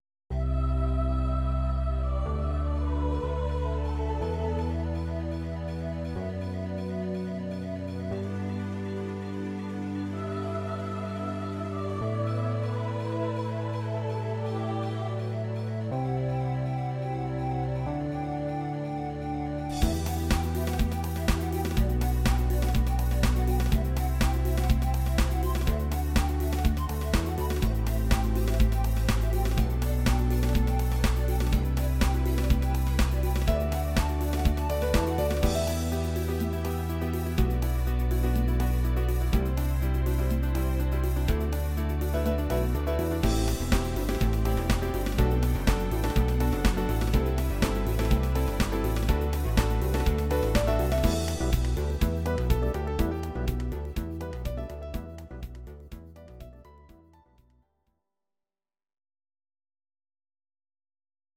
Audio Recordings based on Midi-files
Pop, Disco, 1990s